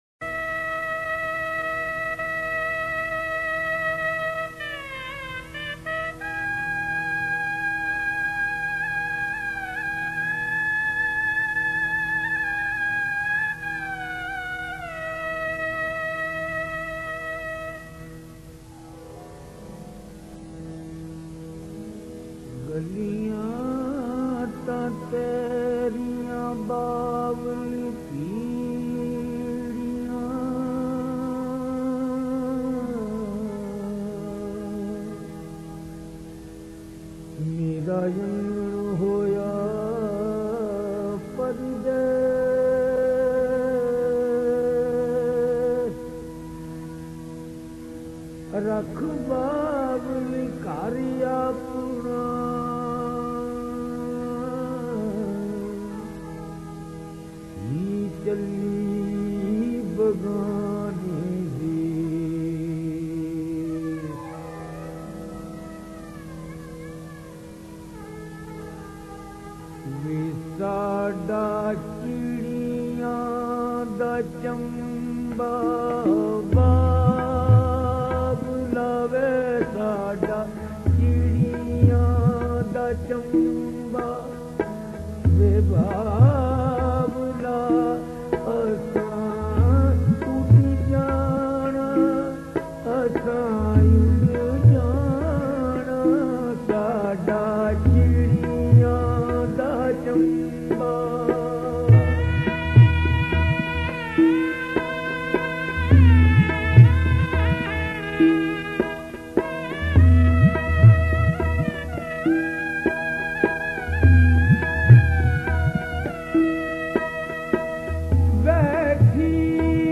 Here is probably the most memorable recording of the Punjabi wedding song,
sada chirian da chamba vay in the voice of Tufail Niazi.
He became an internationally renowned musician and is a great name in Punjabi folk music.